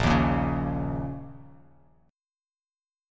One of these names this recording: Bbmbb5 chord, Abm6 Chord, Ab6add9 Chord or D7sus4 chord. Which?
Bbmbb5 chord